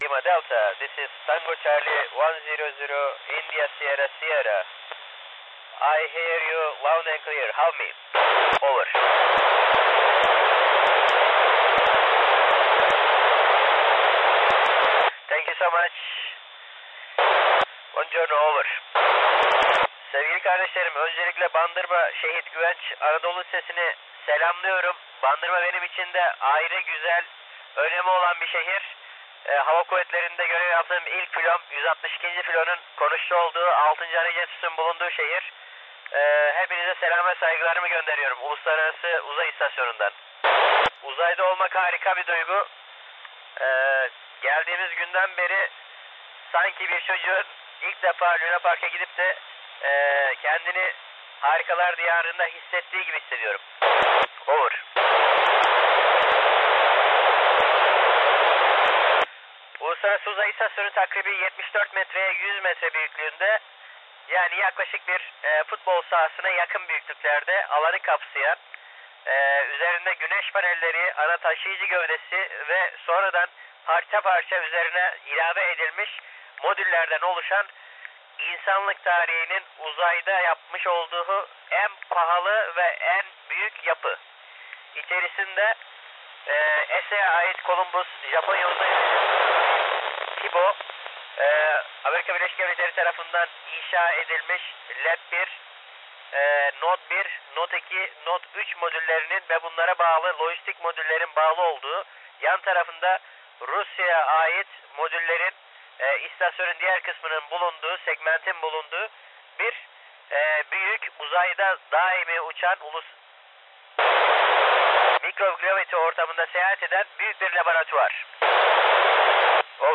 Mes réceptions de l'ISS.